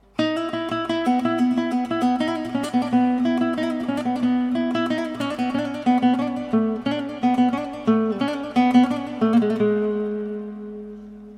misket_suslemeli.mp3